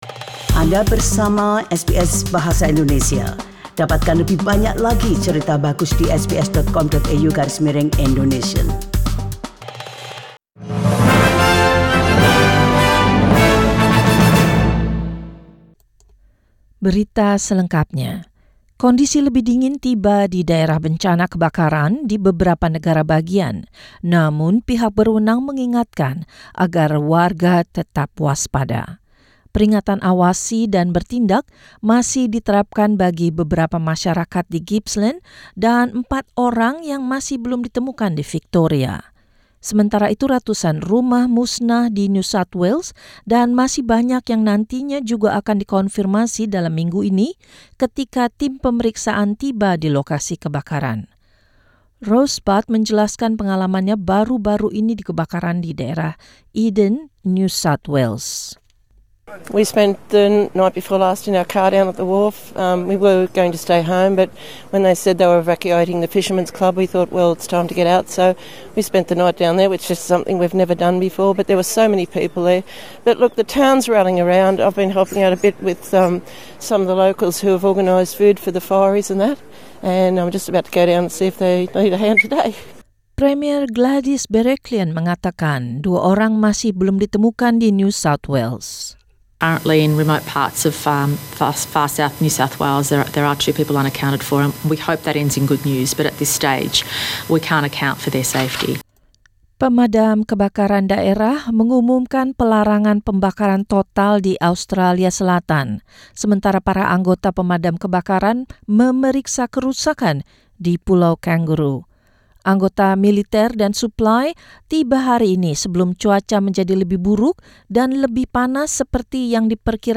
News in Indonesian 6 Jan 2020